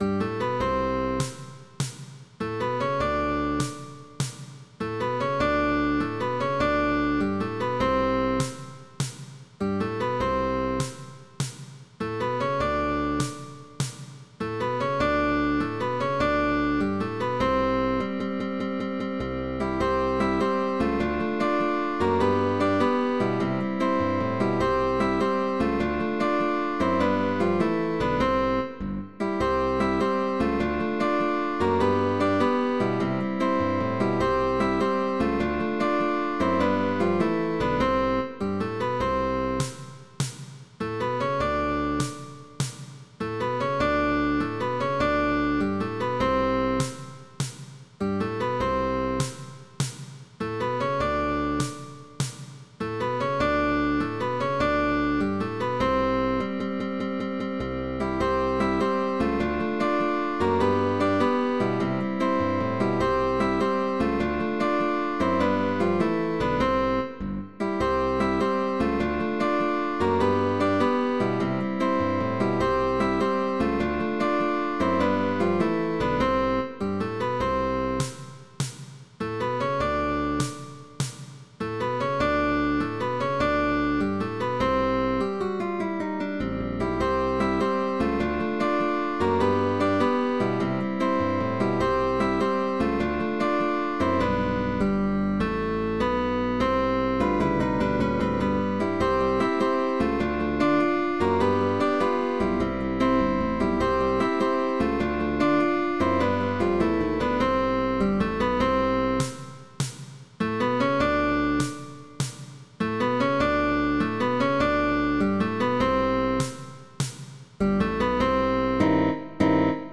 dark and payful.
With optional bass